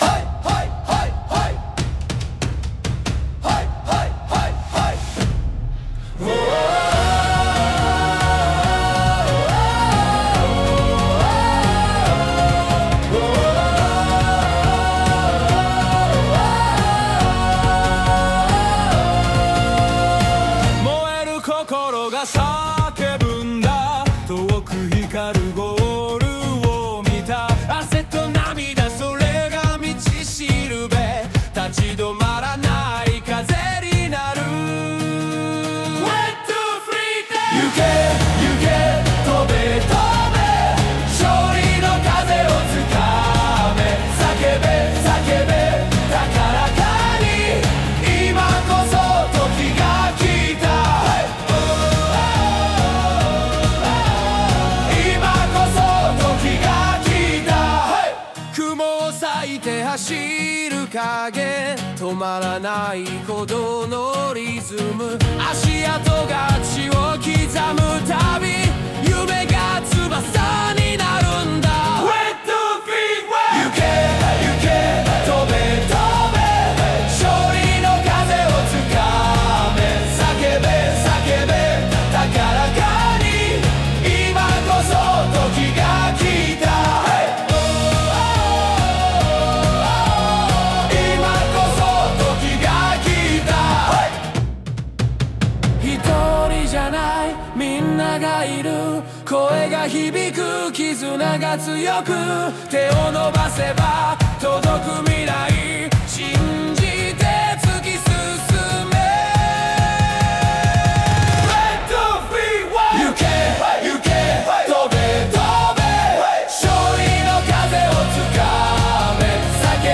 2:22 邦楽「スポーツ系 力強い応援ソング」勝利の風